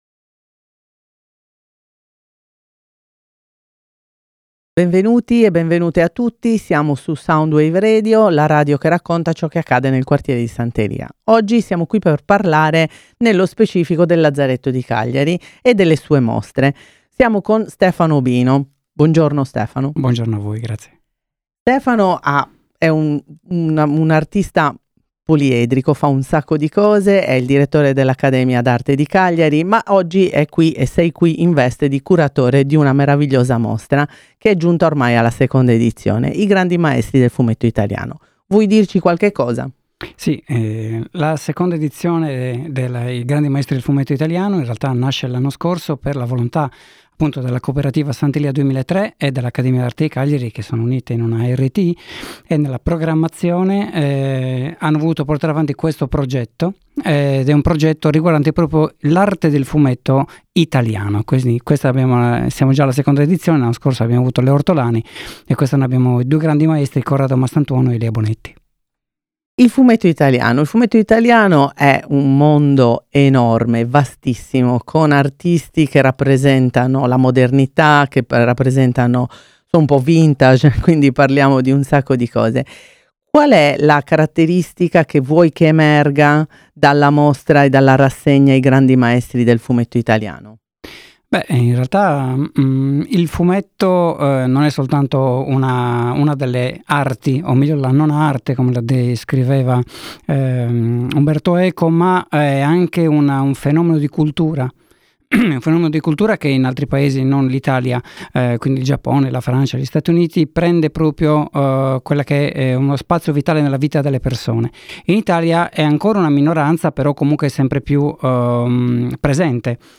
voci narranti del Lazzaretto